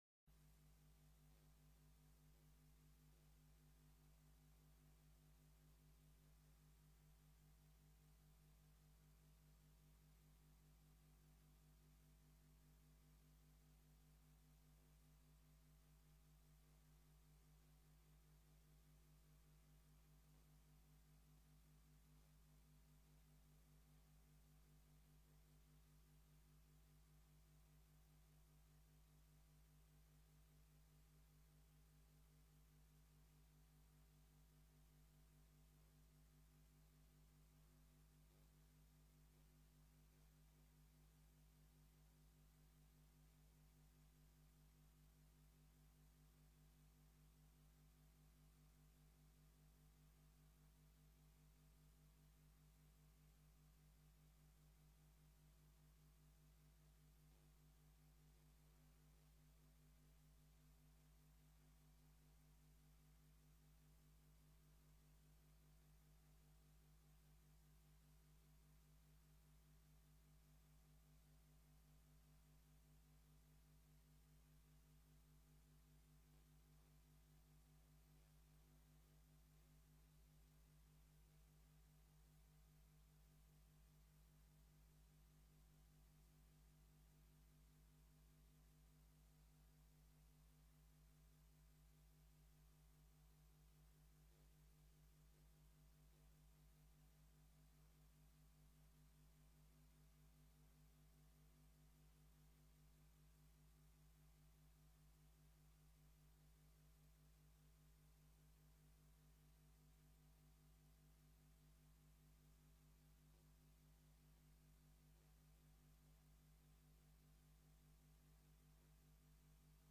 Locatie: Raadszaal
Opening door wethouder Wijbenga